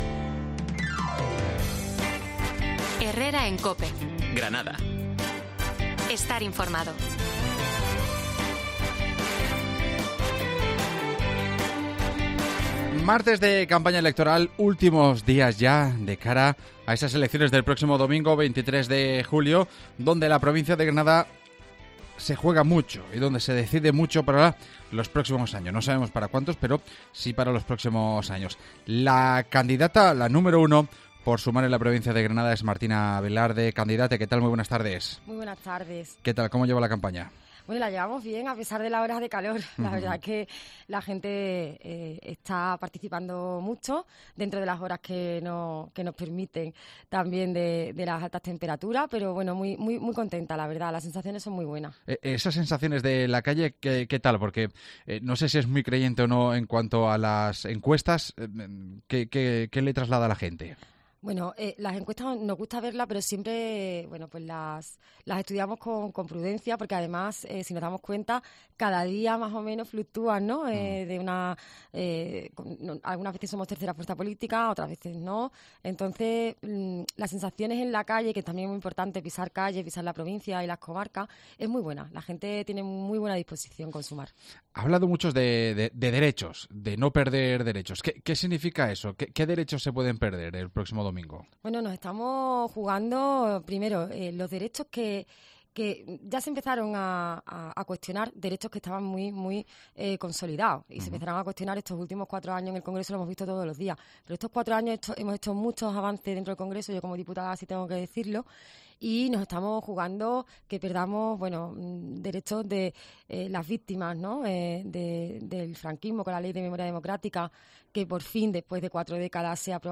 AUDIO: Entrevistamos a Martina Velarde, número 1 de Sumar en Granada